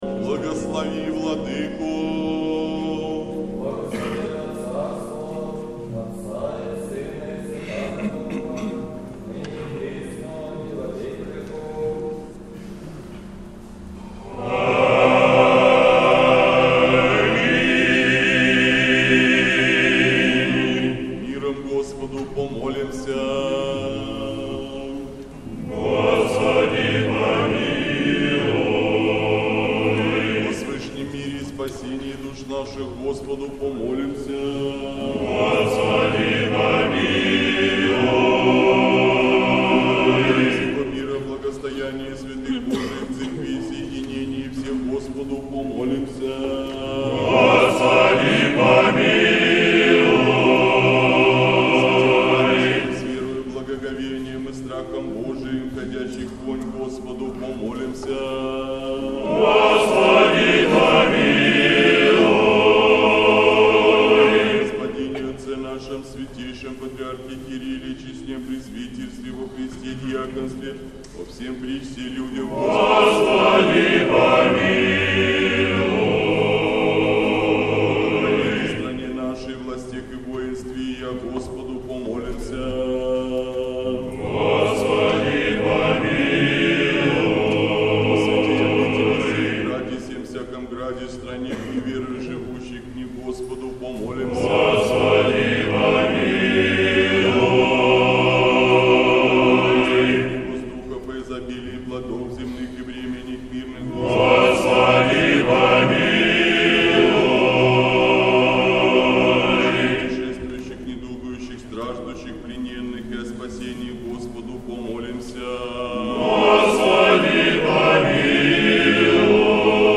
Божественная литургия. Хор Сретенского монастыря.
Божественная литургия в Сретенском монастыре в Неделю 7-ю по Пятидесятнице